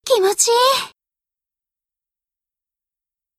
Genre: Nhạc chuông độc